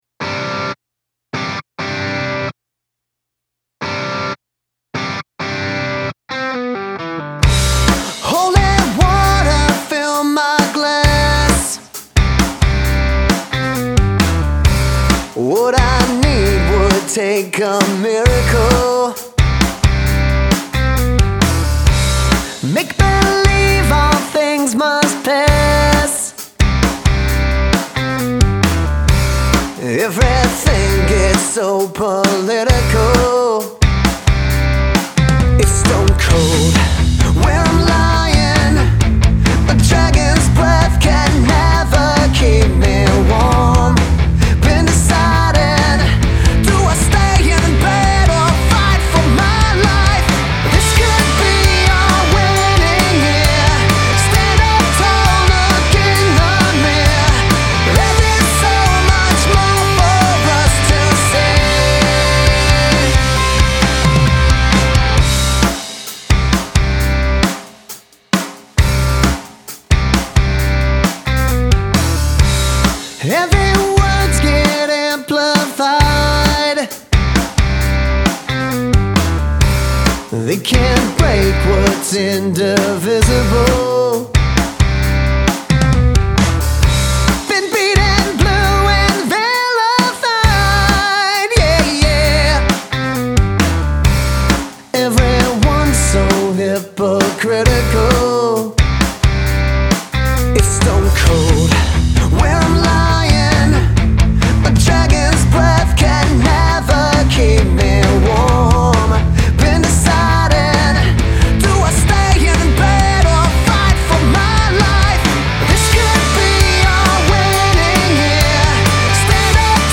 Rock band from Pittsburgh